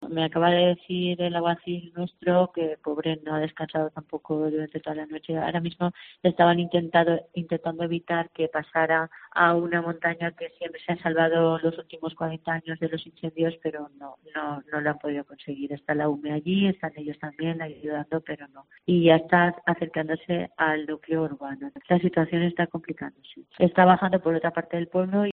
Precisamente, la alcaldesa de Ador, Manuela Faus, relataba en COPE València cómo los bomberos han estado intentando en las últimas horas que el fuego pasara a una zona concreta de la montaña, algo que no han podido conseguir.